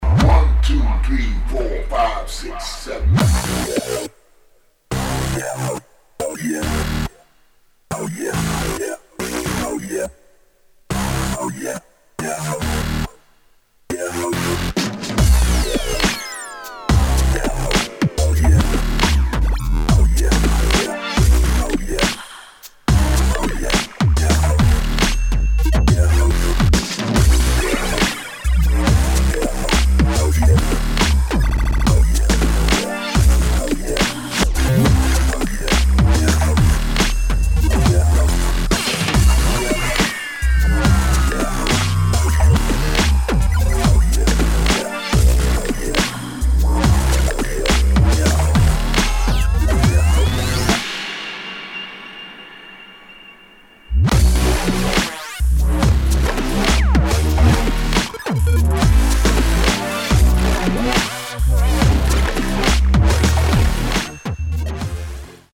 [ DUBSTEP / TRAP / GRIME ]